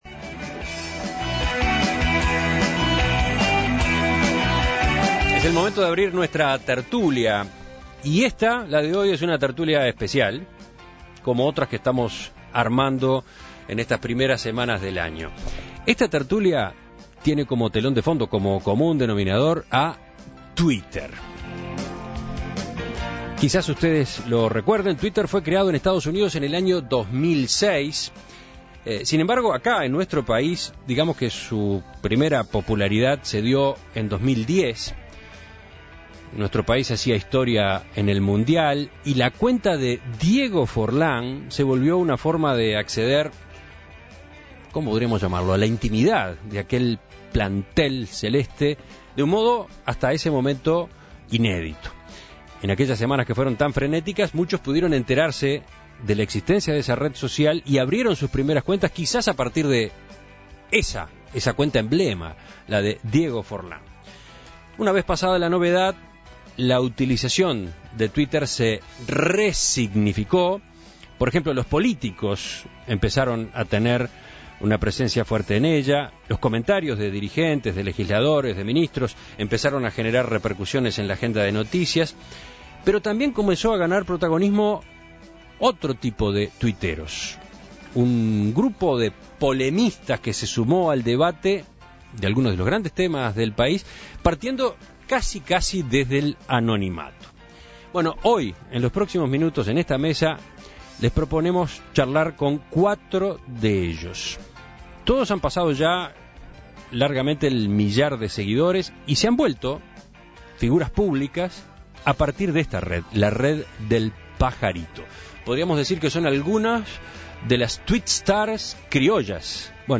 Este martes, en una edición especial de La tertulia, reunimos a cuatro destacados usuarios de Twitter para hablar sobre su relación con esta red social.